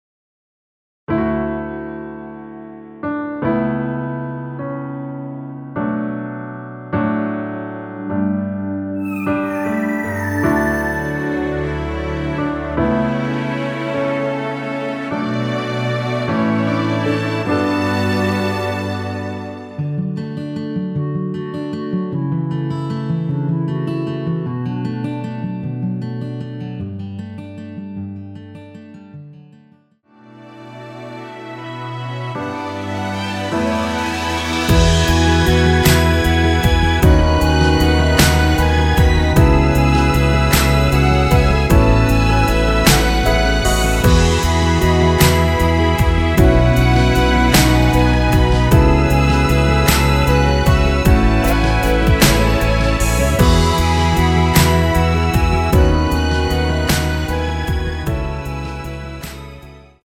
원키 멜로디 포함된 MR입니다.
멜로디 MR이라고 합니다.
앞부분30초, 뒷부분30초씩 편집해서 올려 드리고 있습니다.